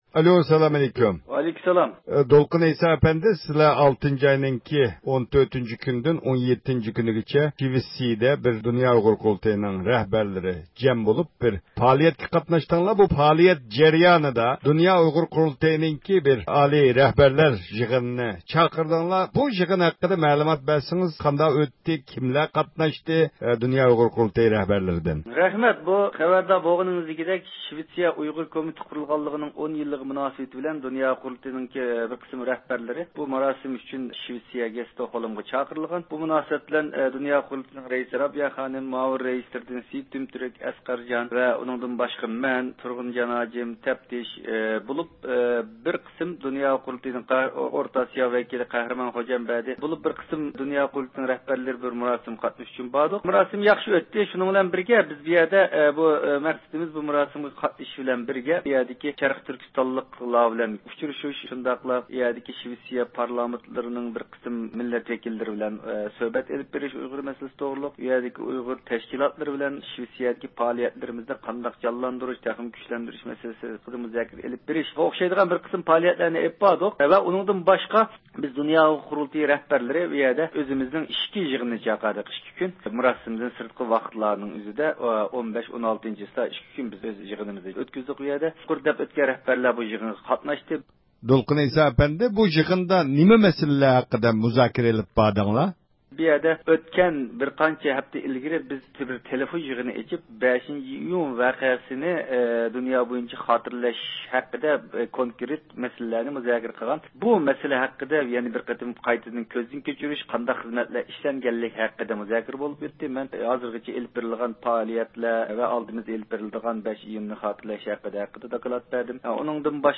بۇ ھەقتە تېخىمۇ تەپسىلىي مەلۇمات ئېلىش ئۈچۈن د ئۇ ق ئىجرائىيە كومىتېتى مۇدىرى دولقۇن ئەيسا ئەپەندى بىلەن تېلېفون سۆھبىتى ئېلىپ باردۇق. ئۇ يىغىندا ماقۇللانغان 7-ئايدىن 2014-يىلى 3-ئايغىچە ئامېرىكا، ياۋروپا دۆلەتلىرى، كانادا ۋە باشقا دۆلەتلەردە ئېلىپ بېرىلىدىغان پائالىيەتلەر ھەققىدىكى پىلان ھەققىدىمۇ مەلۇمات بەردى.